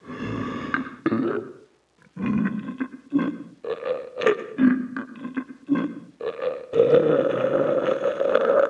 player_zombie_normal_male_idle.mp3